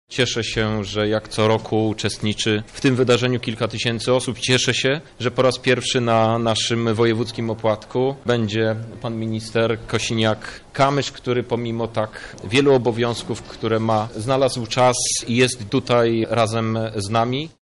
Kilka tysięcy osób wzięło udział w opłatku ludowym w hali MOSiR.
Zbudowaliśmy wielki kapitał, ale musimy mieć świadomość spoczywającej na nas odpowiedzialności – mówi Krzysztof Hetman, szef lubelskich struktur PSL i poseł do Parlamentu Europejskiego